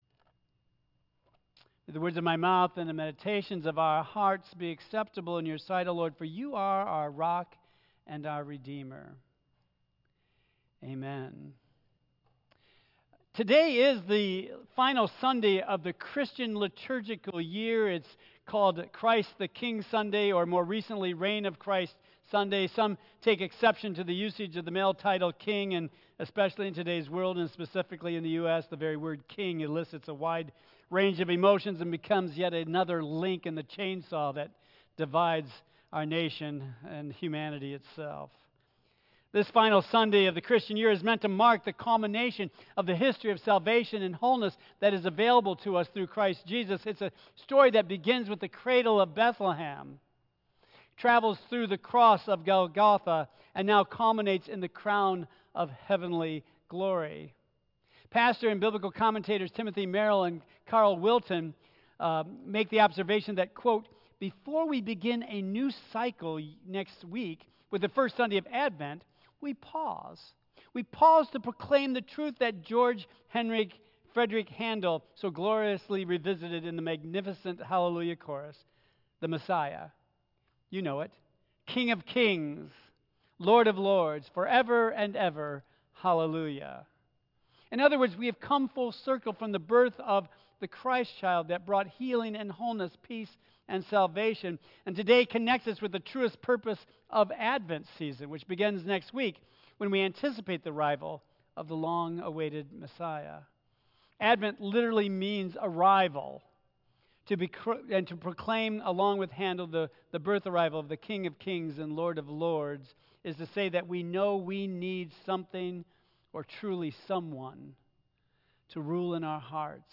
Tagged with Michigan , Sermon , Waterford Central United Methodist Church , Worship